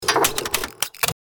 Rocket Launcher Loading 5 Sound Effect Download | Gfx Sounds
Rocket-launcher-loading-5.mp3